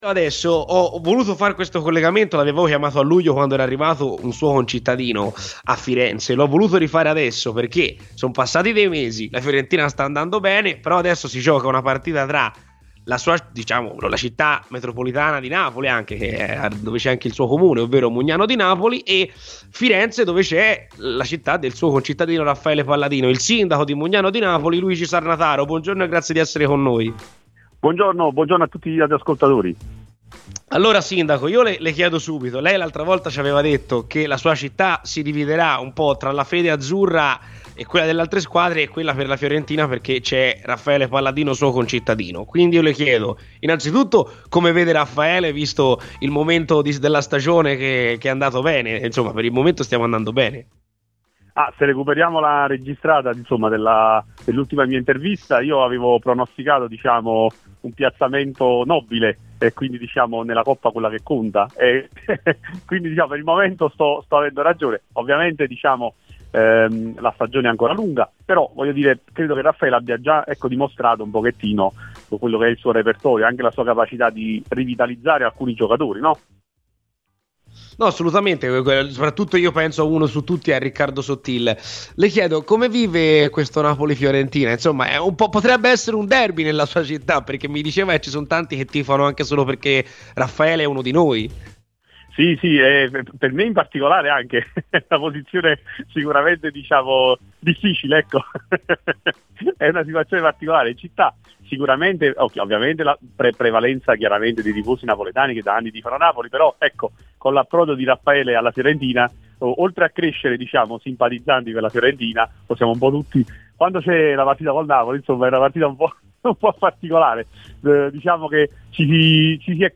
Luigi Sarnataro, Sindaco di Mugnano di Napoli (città natale di Raffaele Palladino), è intervenuto oggi a Radio FirenzeViola nel corso della trasmissione mattutina "C'è polemica".